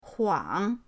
huang3.mp3